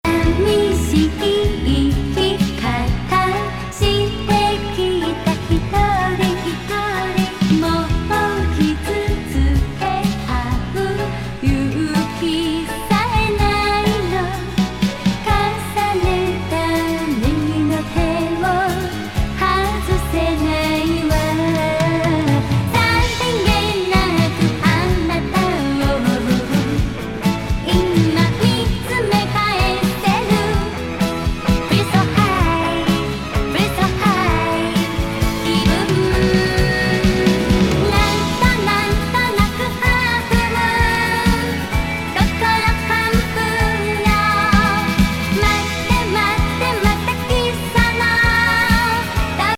両面ともに素晴らしウォール・オブなドリーミー・ナイアガラ・サウンド!